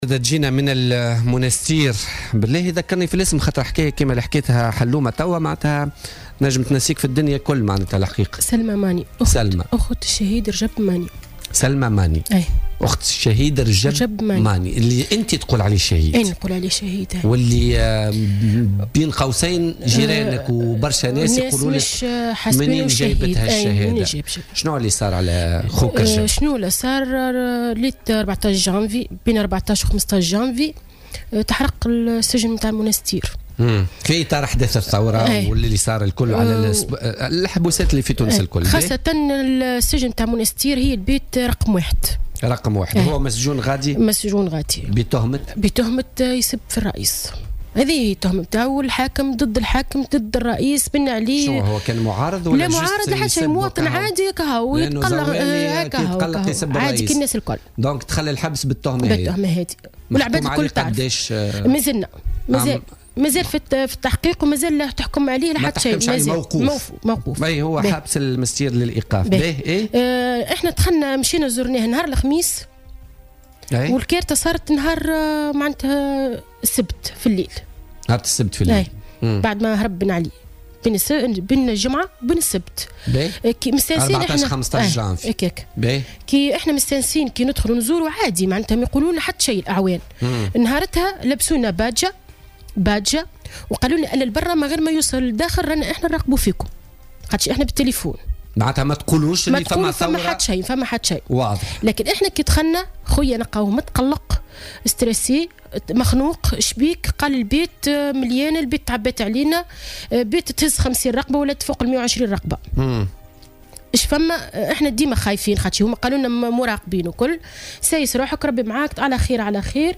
Lors de son intervention sur les ondes de Jawhara FM dans le cadre de l'émission Politica